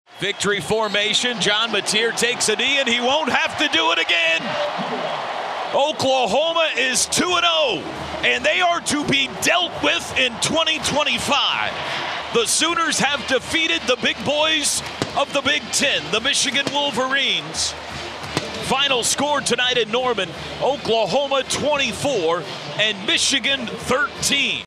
Here is how the final call sounded this past weekend.
OU Final Call 9-8.mp3